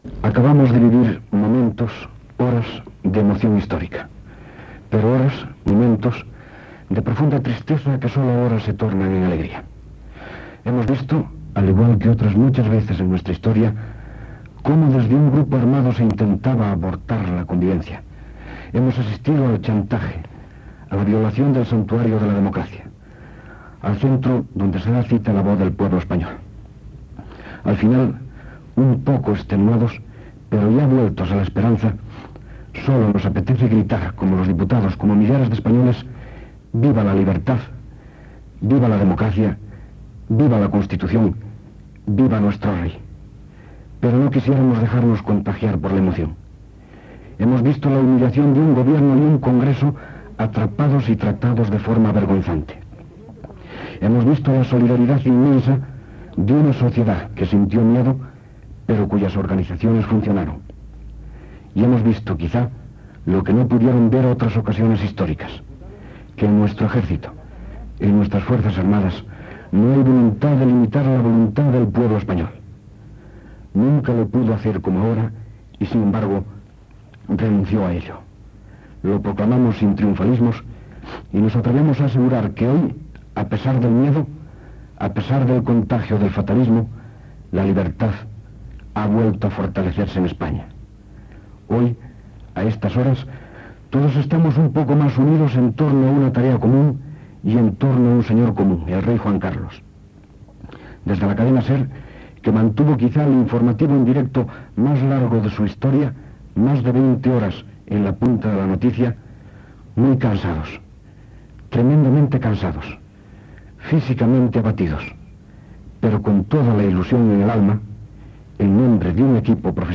Editorial del cap d'informatius de la Cadena SER, Fernando Ónega, al final de la programació especial informativa de l'intent de cop d'estat al Congrés dels Diputats de Madrid
Informatiu
Extret del casset "La SER informa de pleno" publicat per la Cadena SER